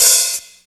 18 OP HAT2.wav